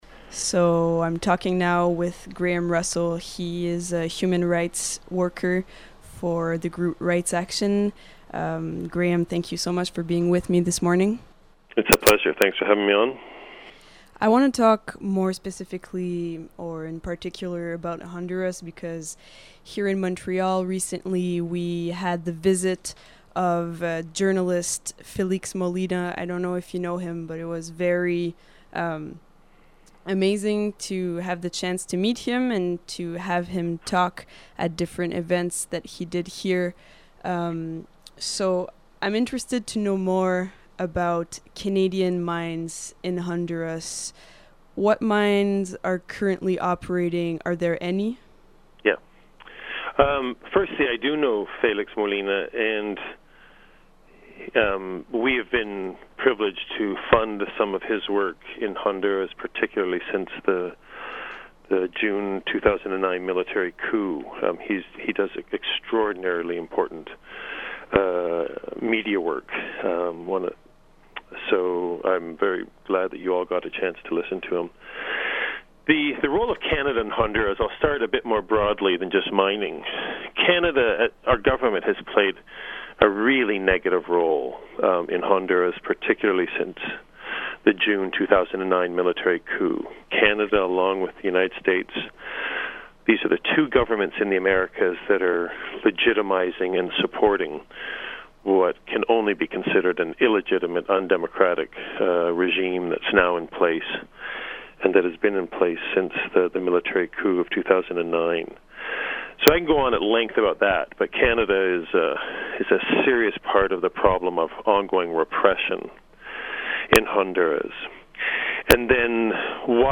Goldcorp and other Canadian companies' deadly role in Central America interview